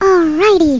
Amiga 8-bit Sampled Voice